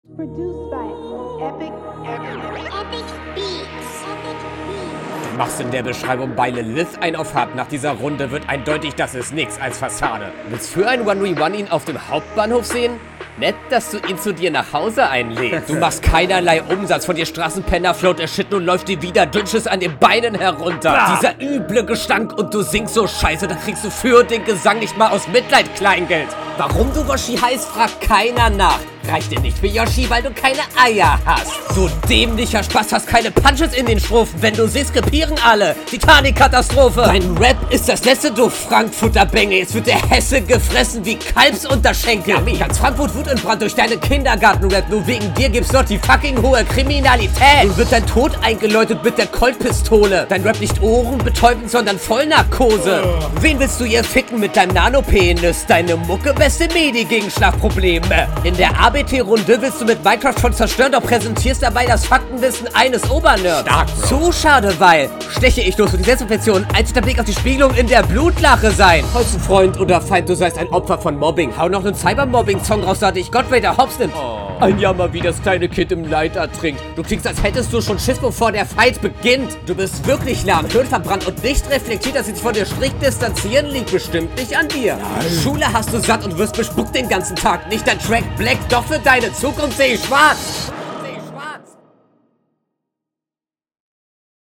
Doubletime nicht nice. Und Stimme klingt hier nach Goblin.